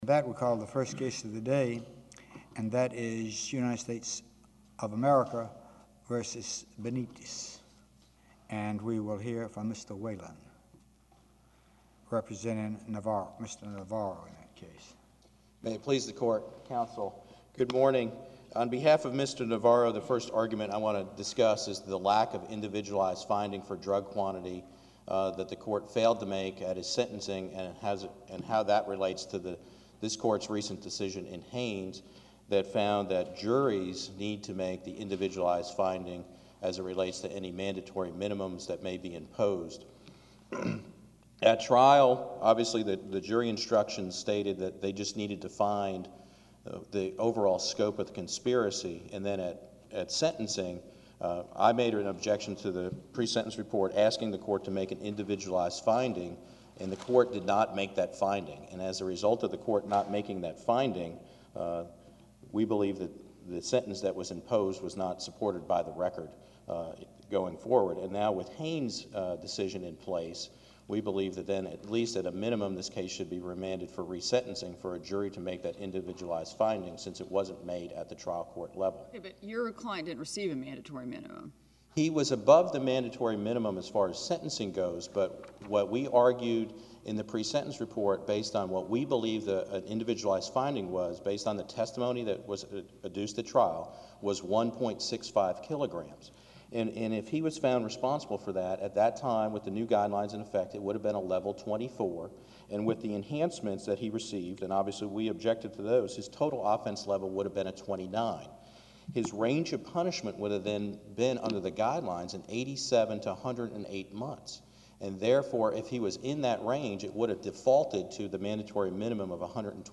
Click here to listen to a recording of an oral argument where the sentencing judge didn’t make a specific finding as to how much drugs the defendant was responsible for. This is important because the 5th circuit decided a case on October 15th finding that a jury must make that finding beyond a reasonable doubt if a person is subject to a mandatory minimum sentence.